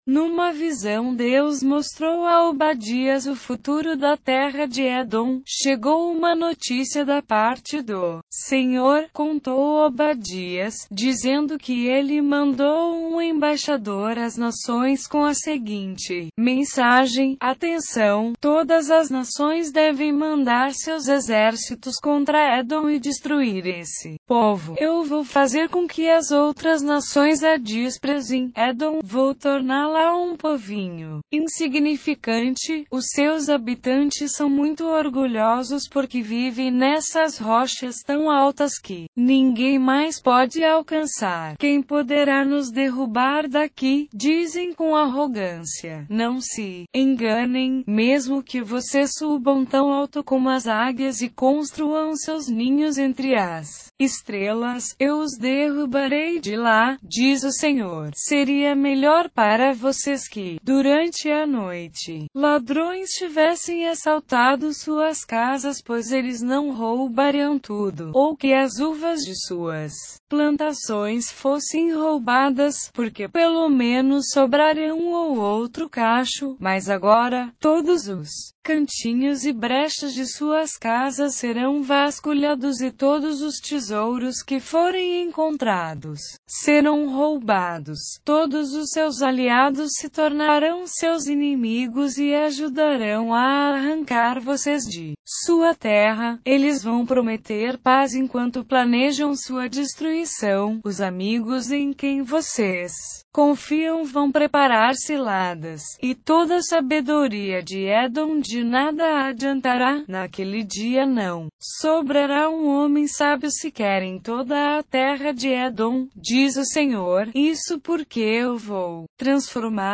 Leitura na versão Novo testamento Versão Palavra Viva/Velho testamento Bíblia Viva - Português